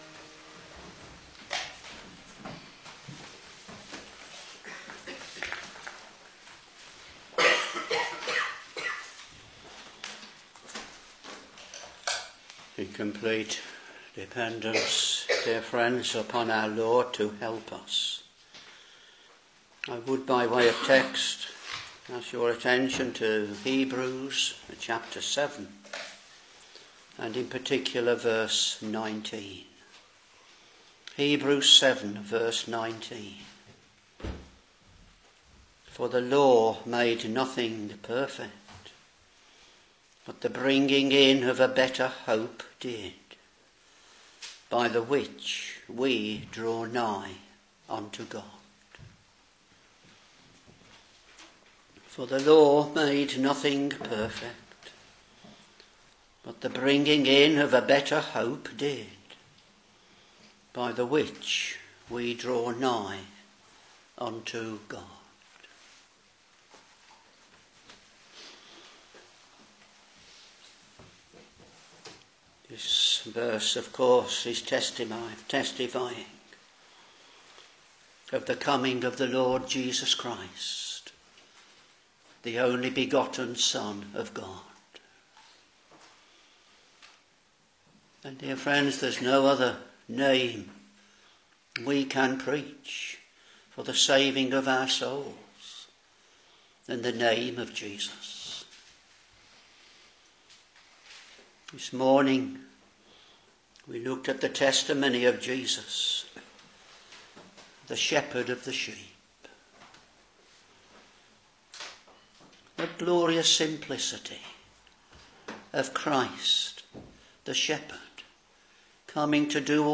Back to Sermons Hebrews Ch.7 v.19 For the law made nothing perfect, but the bringing in of a better hope did; by the which we draw nigh unto God.